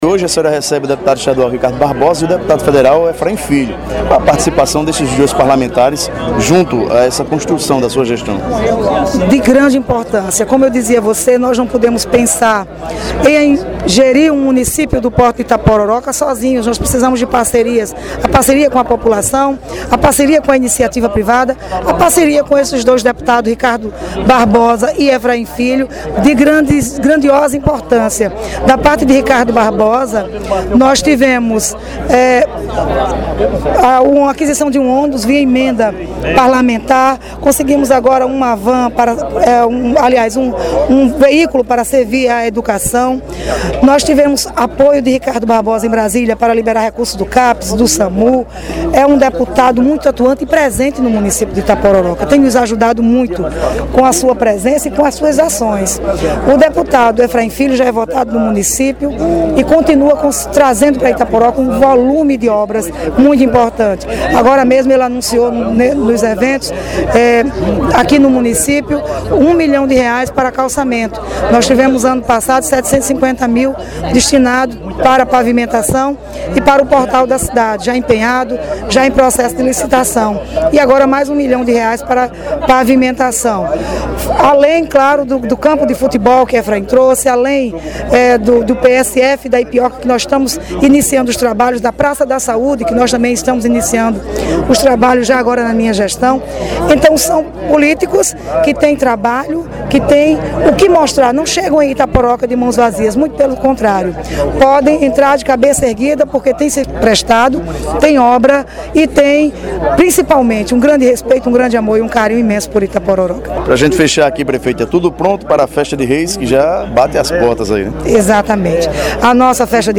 Ouça entrevista com a prefeita Elissandra Brito: